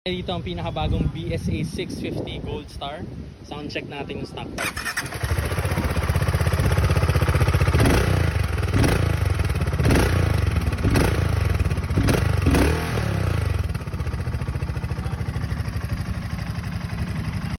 Goldstar 650 Mp3 Sound Effect BSA IS BACK! Goldstar 650 stock pipe soundcheck!